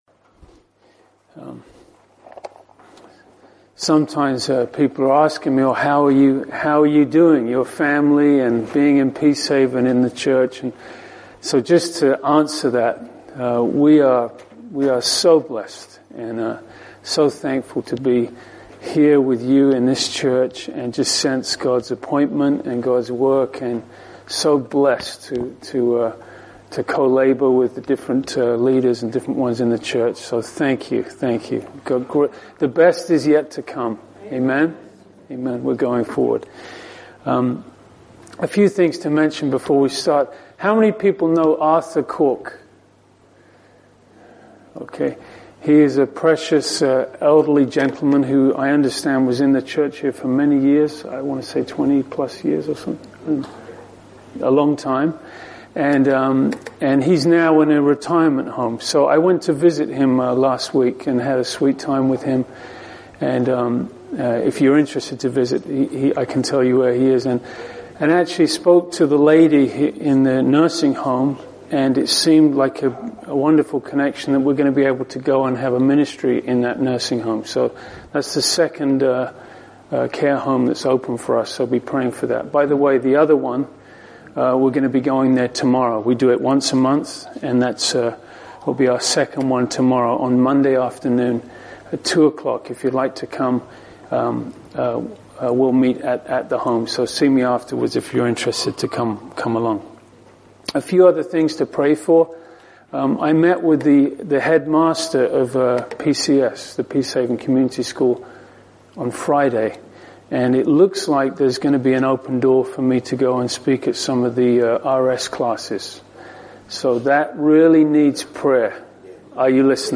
In this gospel sermon, the back ground story of Nebuchadnzzars' dream and Daniels subsequent interpretation is used to as an illustartion of the essential quest of lost men and God's answer in through the glorious gospel.